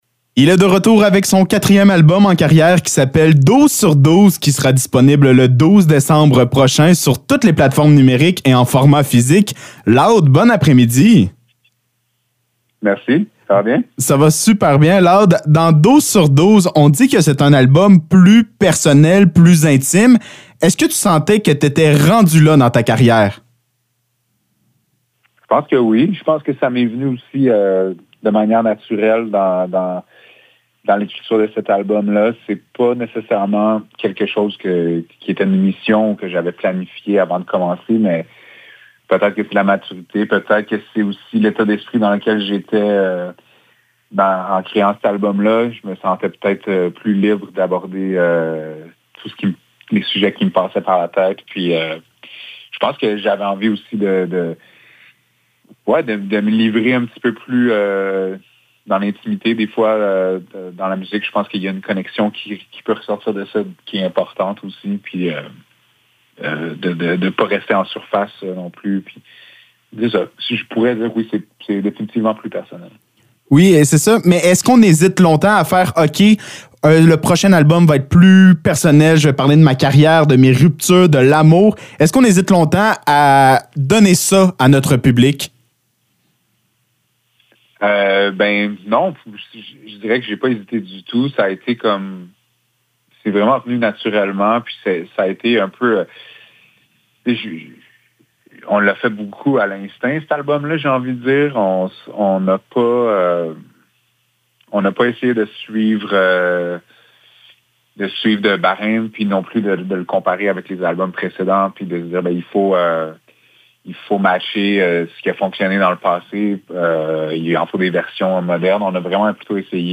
Entrevue avec Loud
Entrevue avec Loud concernant la sortie de son tout nouvel album Douze sur Douze sorti le 12 décembre dernier.